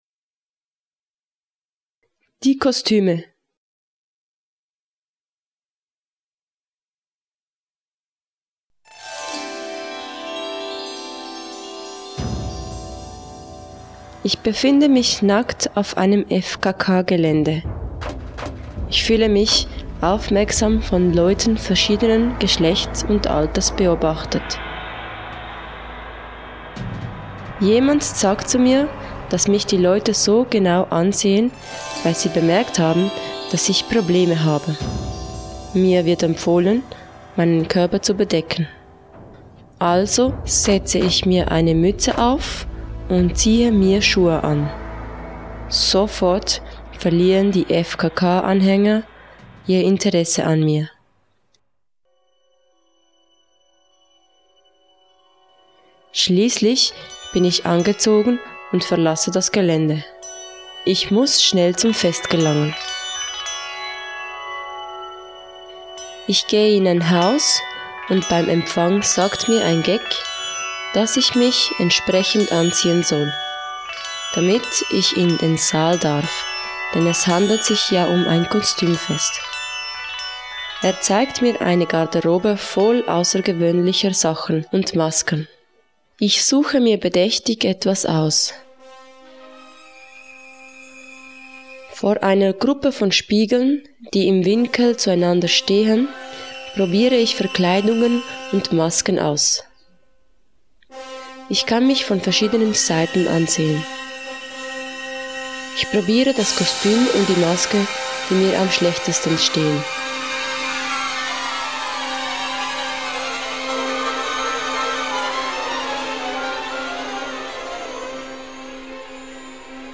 Geleitete Erfahrung